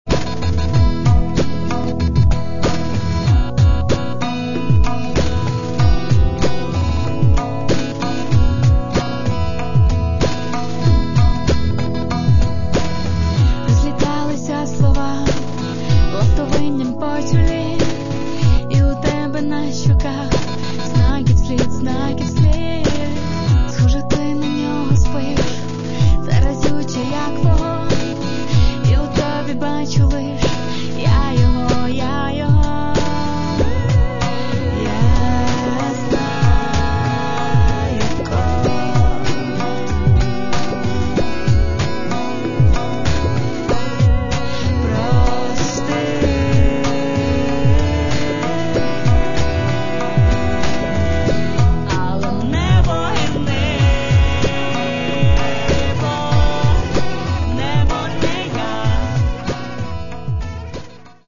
Каталог -> Рок та альтернатива -> Електронна альтернатива
І жіночий вокал тут теж виглядає далеко не випадковим.